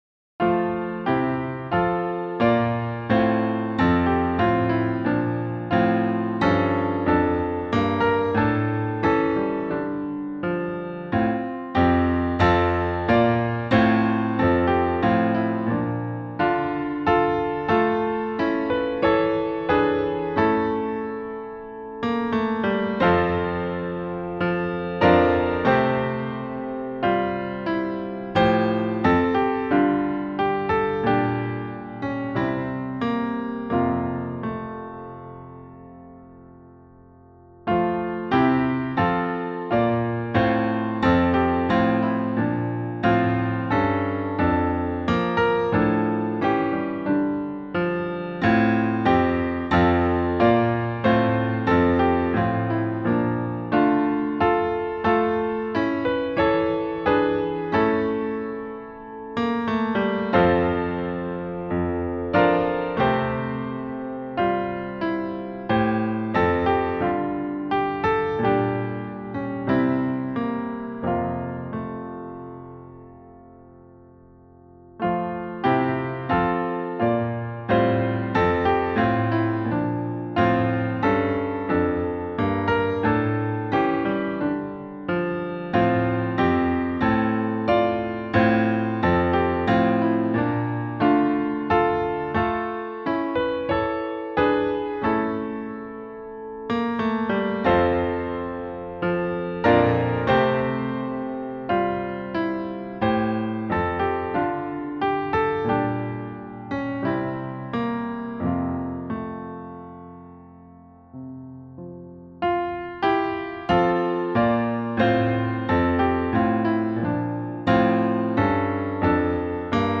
Here is a recording in the key of F. Click Here to Download the music or use the player to listen below.
For-the-Childrens-Sake-in-F.mp3